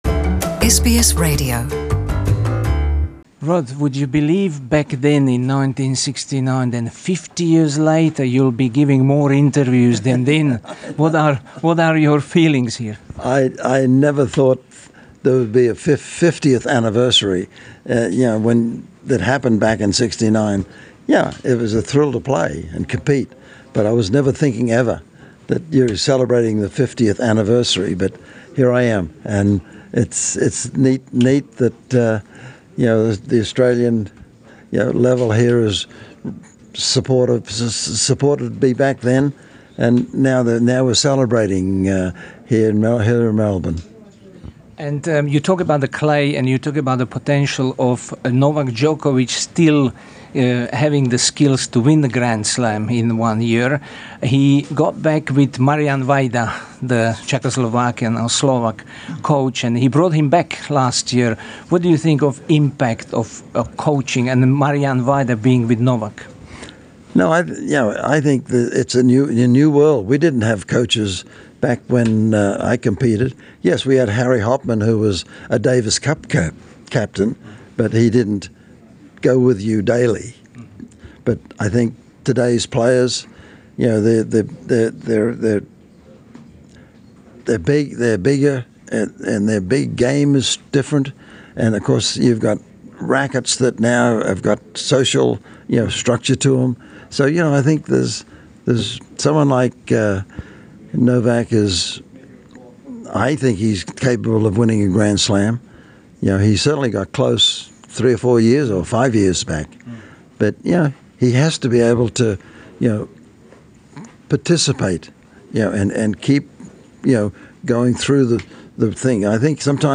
Наш колега са словачког програма придружио се Род Лејверу и упитао га да ли је икада помислио да ће 50 година после овог значајног постигнућа давати више интервјуа него икад и такође да каже о својој недавној изјави " да мисли да постоји само један играч у модрној ери тениса за кога он верује да је у стању да комплетира све четири слем титуле у каледнарској години, Ђоковић."